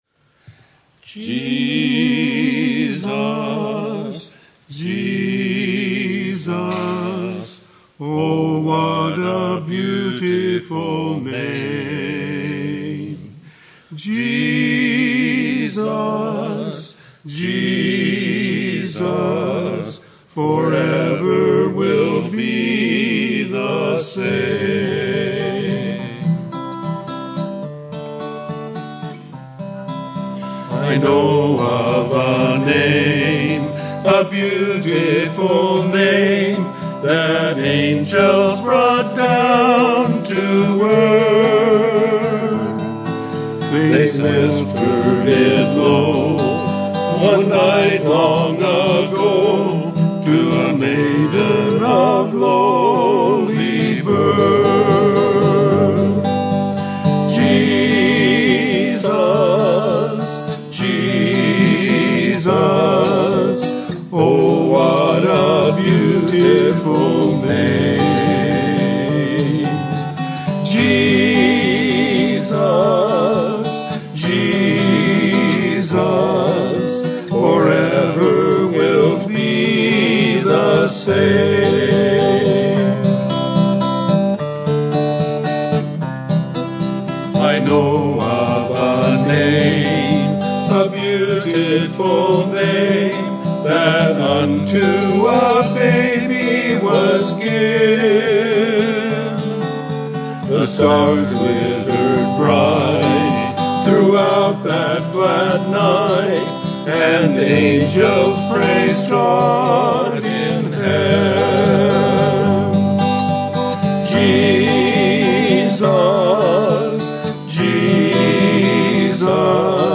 Worship Service
Audio-Full Service